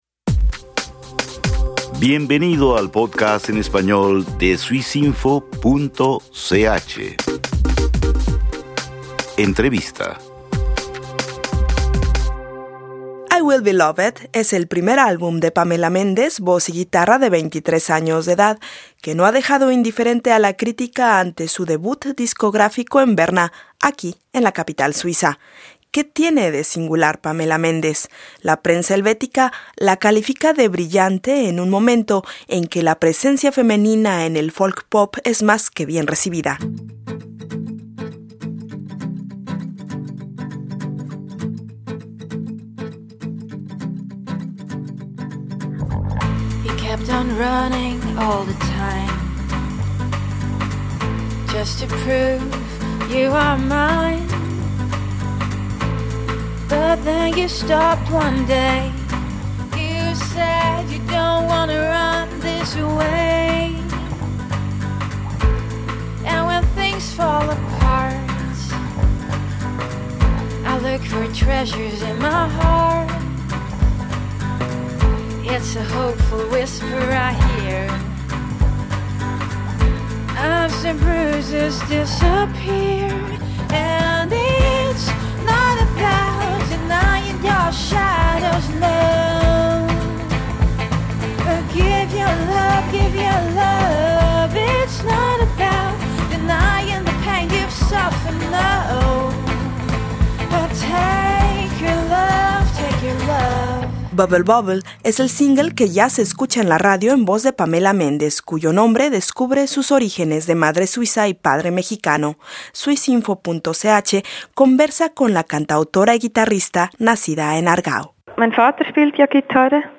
Su multifacética voz y su guitarra.